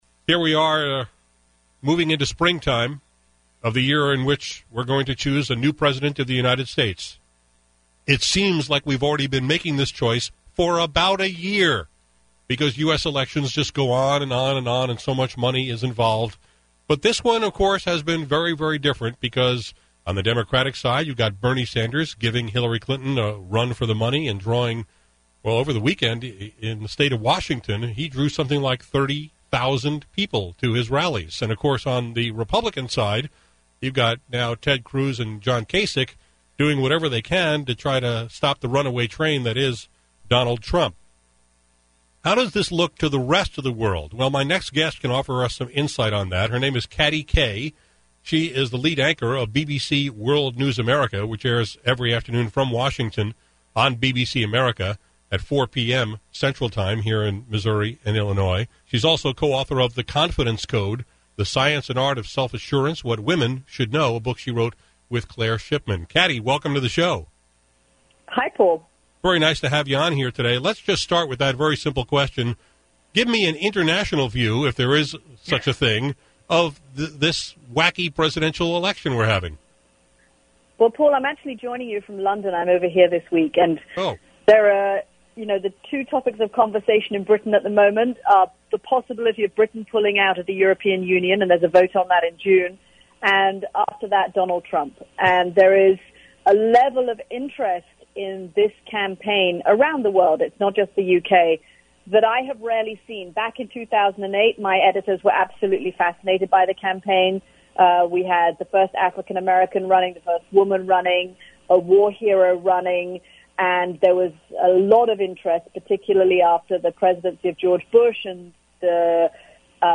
For a wider perspective on this bizarre presidential election year, here’s my conversation with Katty Kay of BBC World News America.
Katty Kay is lead anchor of BBC World News America, which airs weekdays at 4pm CT on BBC America.